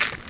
default_gravel_footstep.3.ogg